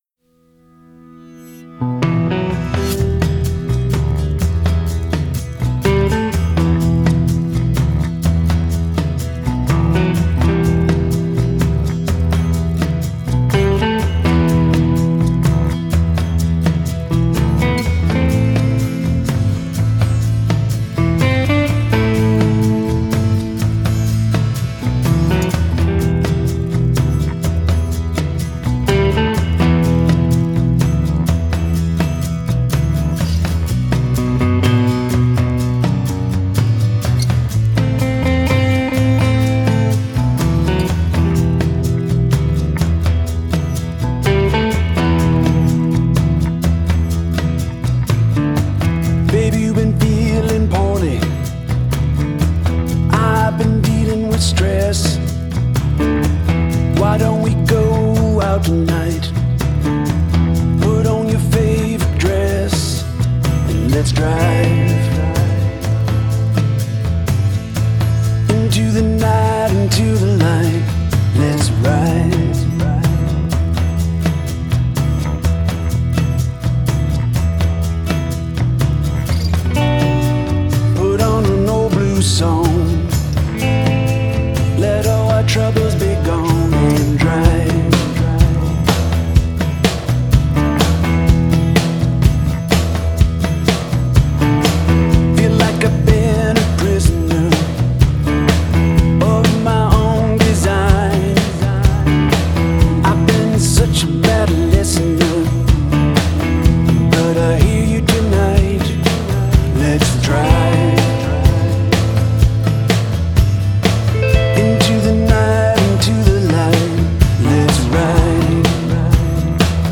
Genre: Blues Rock